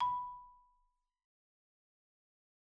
Marimba_hit_Outrigger_B4_loud_01.wav